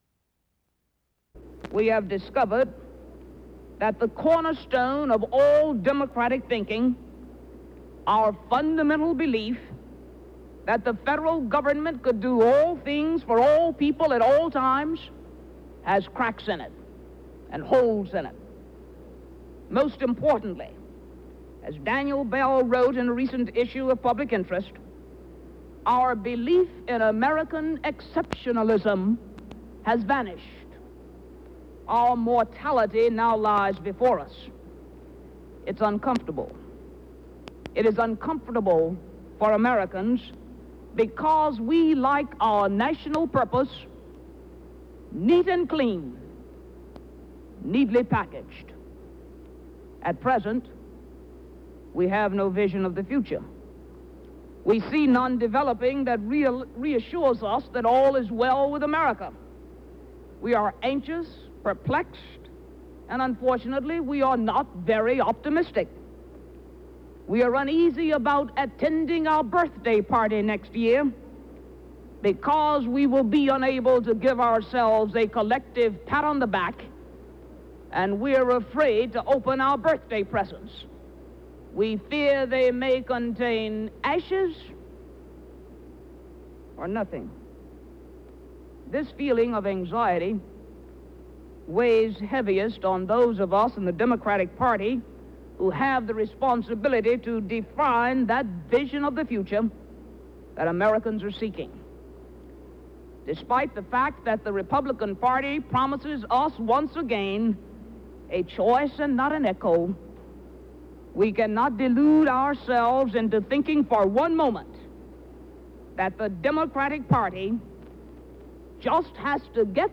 U.S. Congresswoman Barbara Jordan speaks to an audience of young Democrats